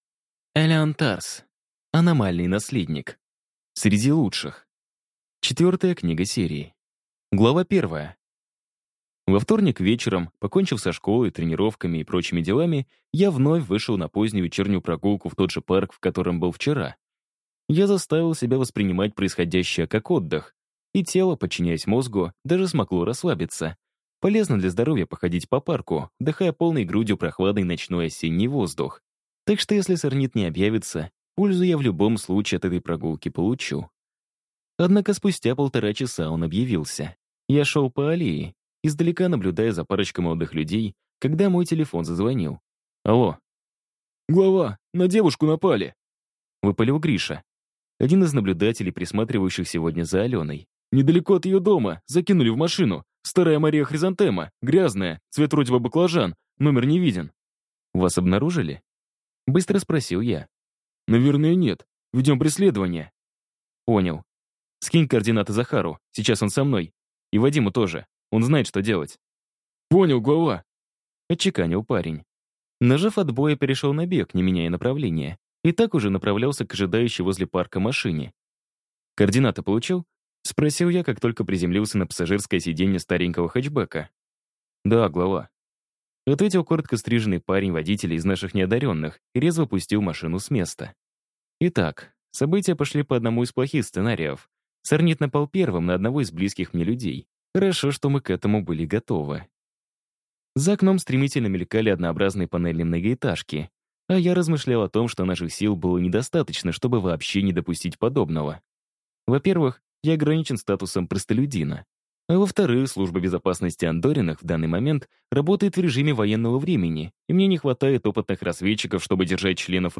Аудиокнига Аномальный наследник. Среди лучших | Библиотека аудиокниг